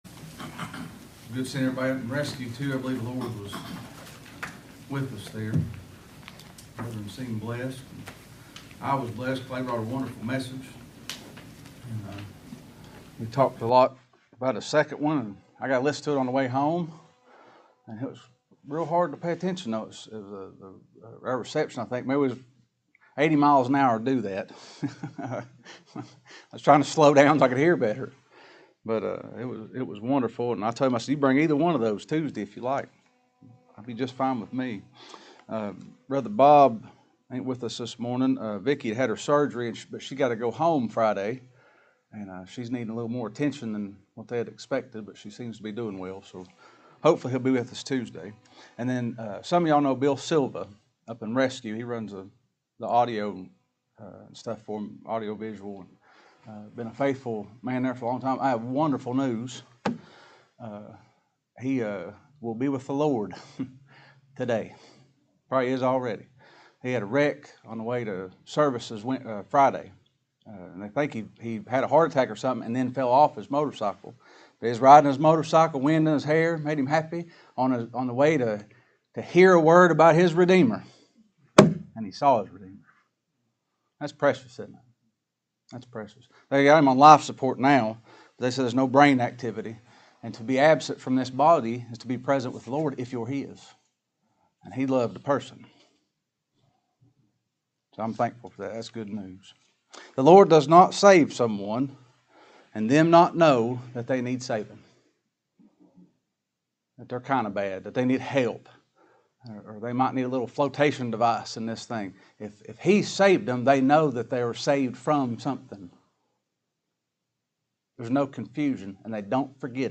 Judgement and Intercession | SermonAudio Broadcaster is Live View the Live Stream Share this sermon Disabled by adblocker Copy URL Copied!